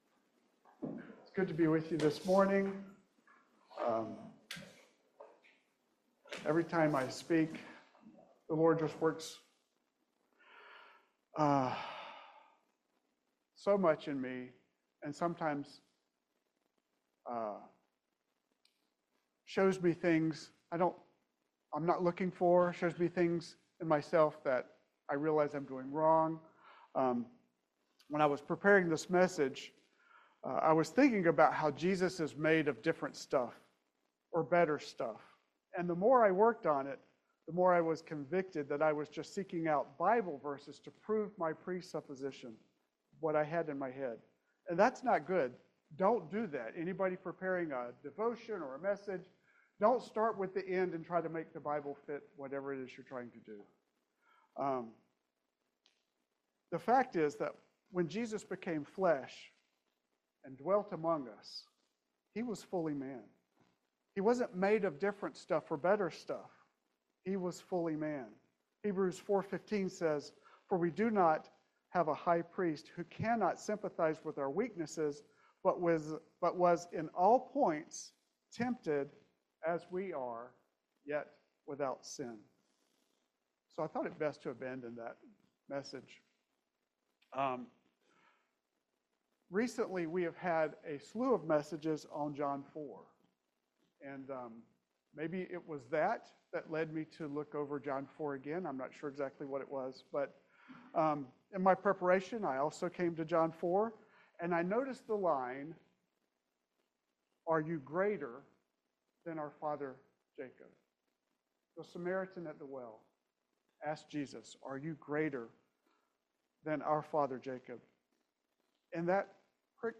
John 4 Service Type: Family Bible Hour Jesus is the Messiah